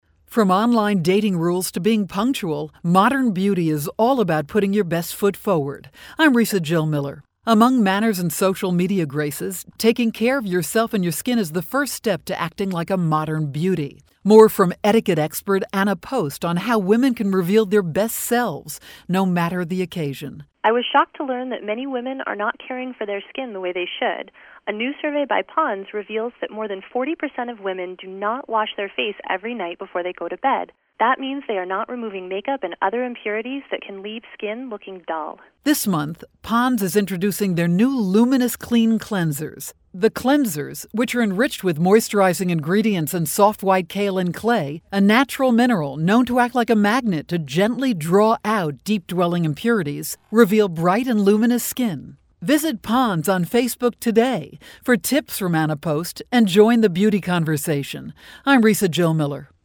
August 28, 2012Posted in: Audio News Release